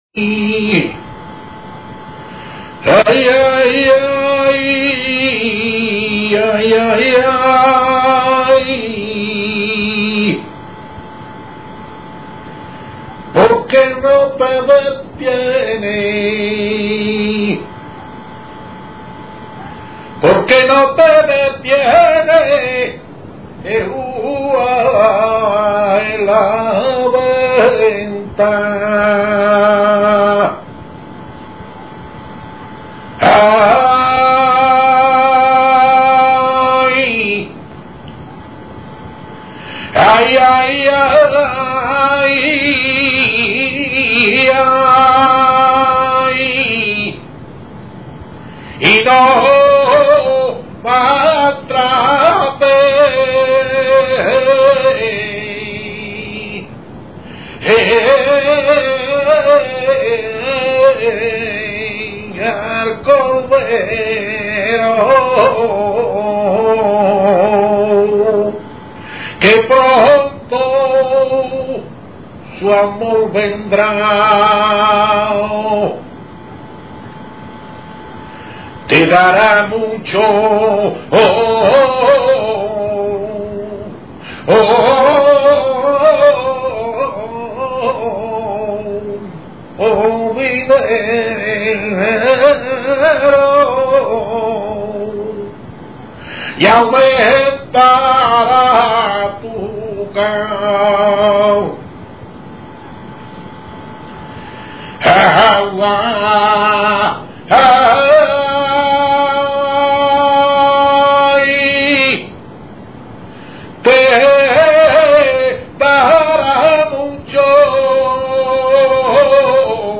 Cantes: Soleá, Saeta y Fandangos.
Saeta1 Saeta2
Sonido MP3 grabaciones de Radio Mairena FM.
No tiene una potente voz aunque sí sabe menejar el cante.